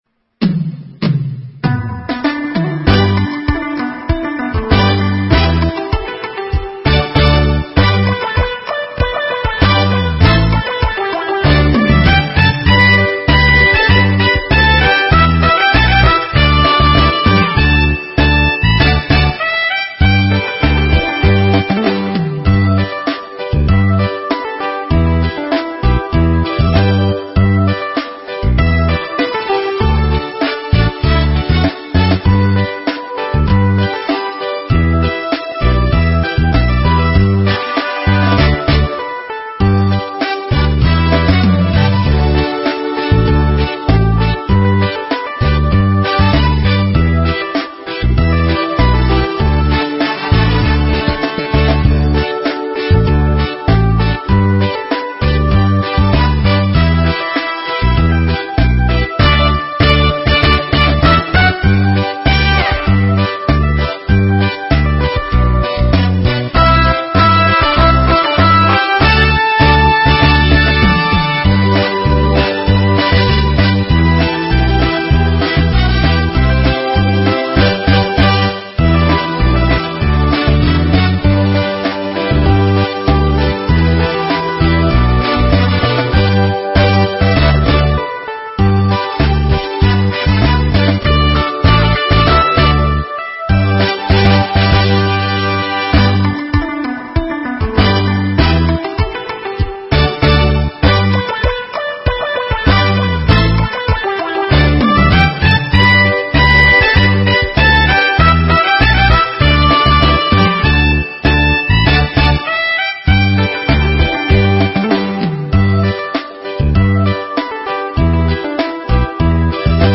【★반주.명상.가사★】/♬~반주.연주.경음악